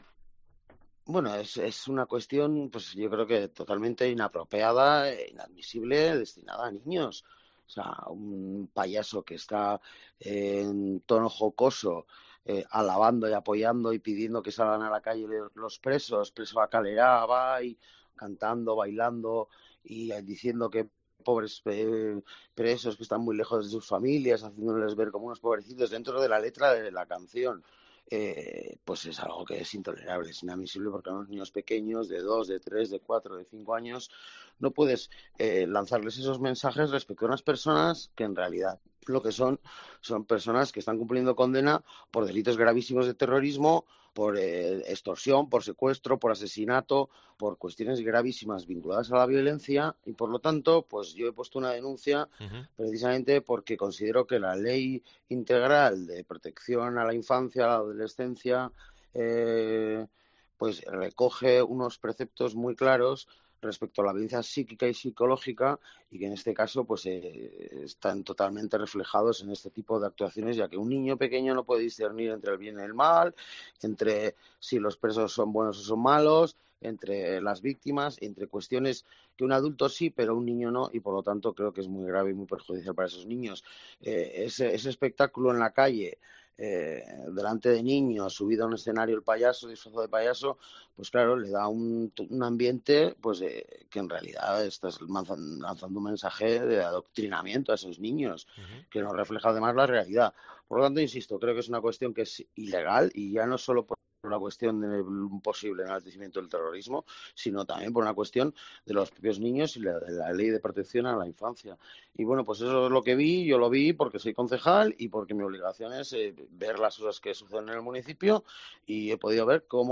Carlos García explica en COPE Euskadi que el pasado sábado presenció personalmente cómo el payaso Porrotx alababa a presos de ETA en una actuación organizada en esta localidad por la plataforma de apoyo a estos reclusos Sare.